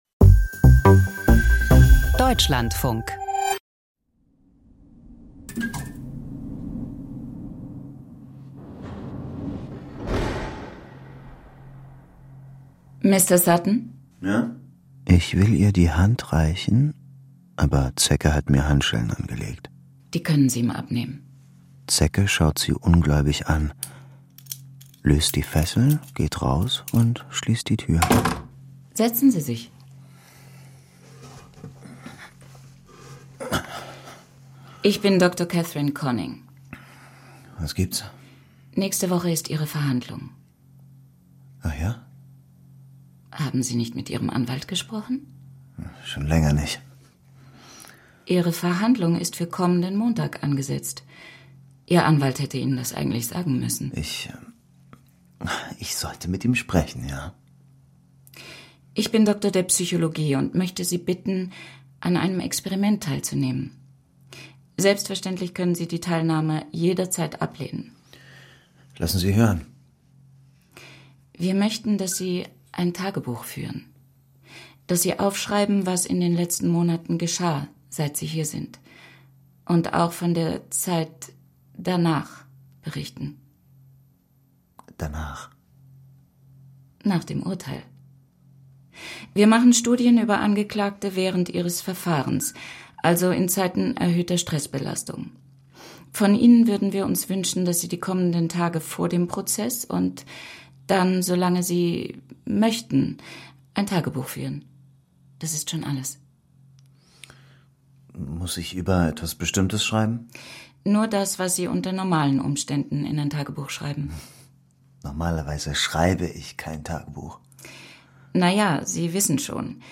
Justizdrama nach einer wahren Geschichte